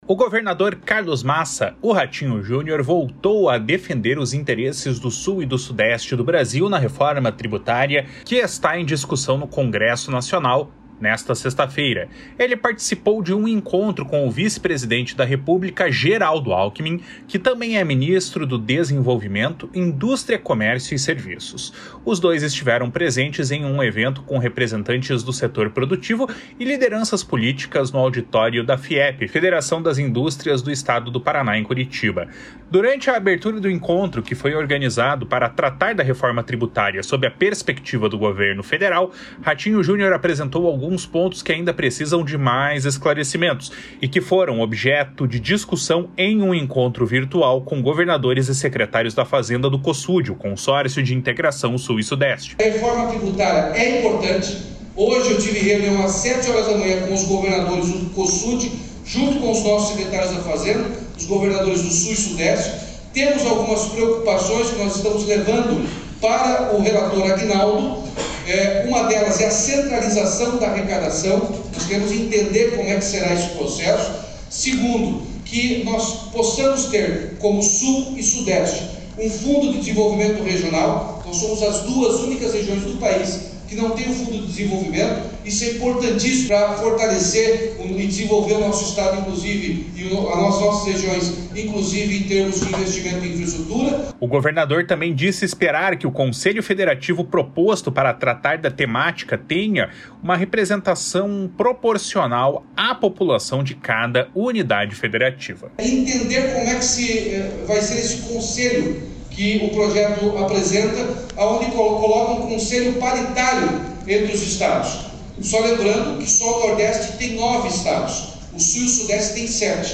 Os dois estiveram presentes em um evento com representantes do setor produtivo e lideranças políticas no auditório da Fiep, Federação das Indústrias do Estado do Paraná, em Curitiba.
Em discurso, o vice-presidente fez um contexto geral sobre a visão da União acerca da reforma tributária, que segundo ele não vai significar mudanças na distribuição dos recursos, mas uma simplificação que aumente a competitividade da economia nacional em médio e longo prazos.